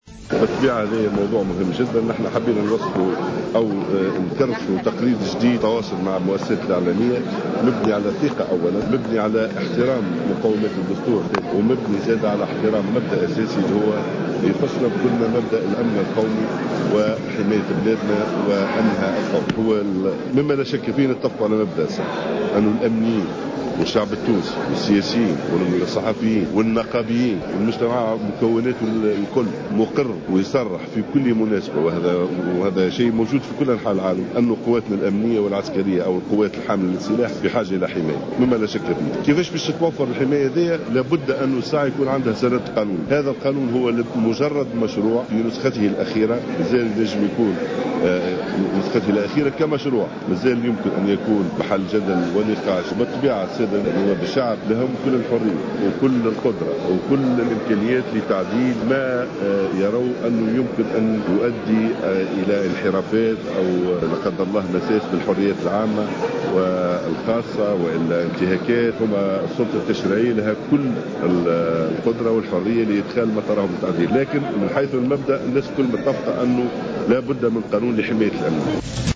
Le ministre de l’Intérieur, Mohamed Najem Gharsalli, a déclaré ce mercredi 29 avril 2015, lors de la journée d’études tenue à la caserne de l’Aouina, que la situation sécuritaire s’est remarquablement améliorée au fil des jours certes, mais que les forces de sécurité rencontrent encore quelques difficultés avec les groupes terroristes retranchés dans les montagnes.